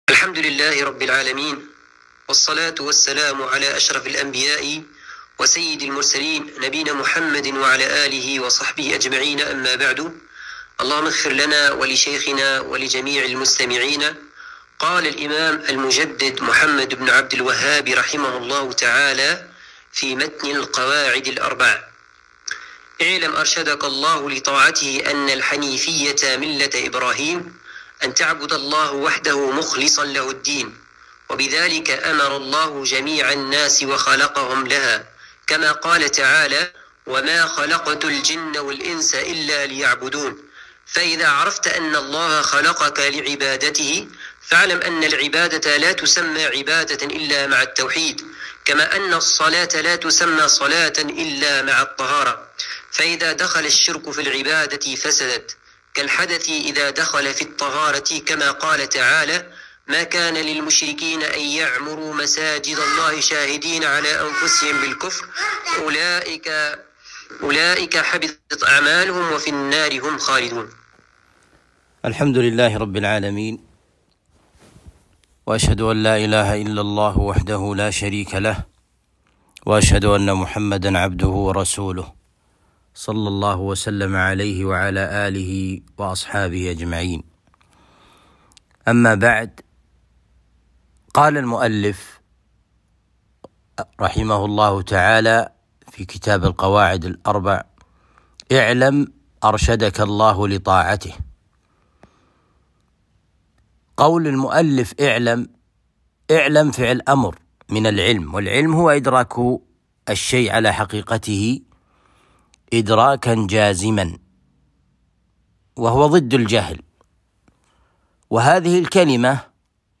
القواعد الأربع الدرس الثاني